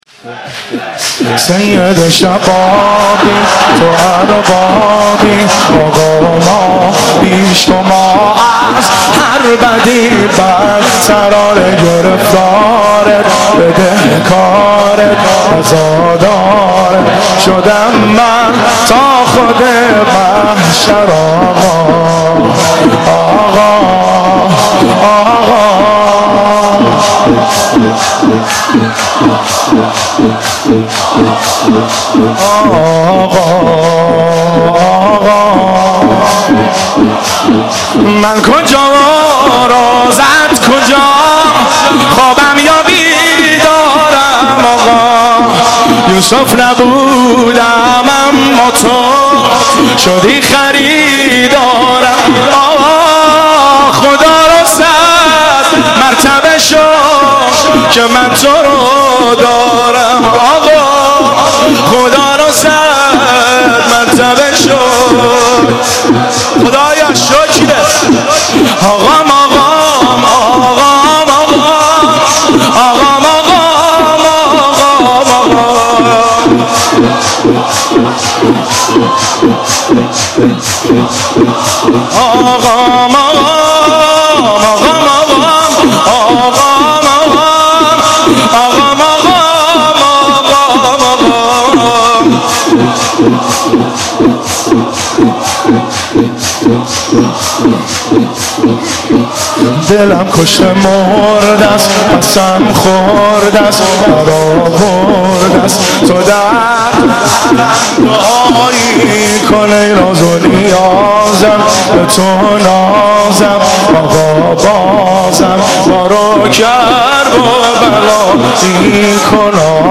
مداحی
شور) شب عاشورا محرم1394 هیئت بین الحرمین